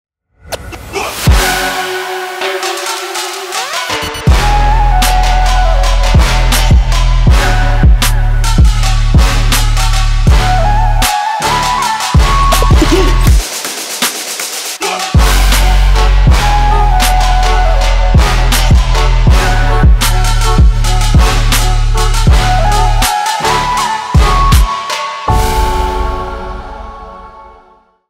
• Качество: 320, Stereo
жесткие
восточные мотивы
Electronic
без слов
Trap
качающие
Флейта
эпичные